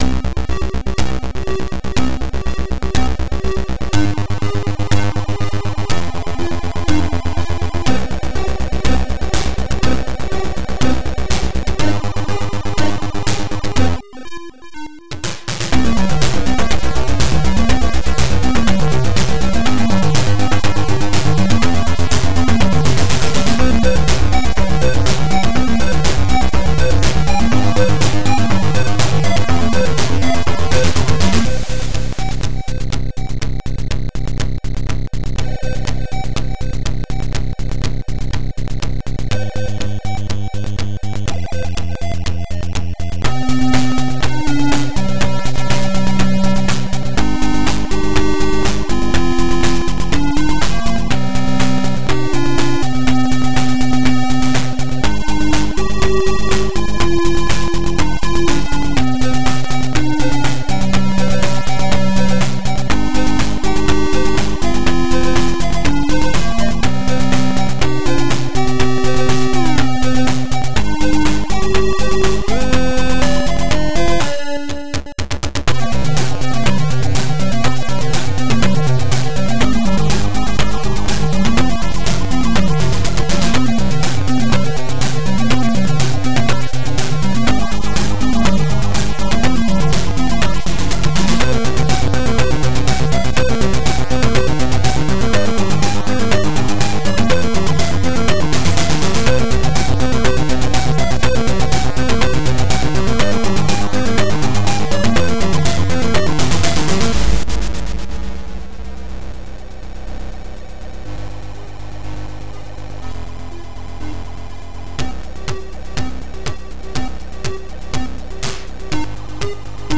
ZX Spectrum + TS
vt2 (Vortex Tracker 2.6 TS)
• Два звуковых чипа AY-3-8912 / YM2149.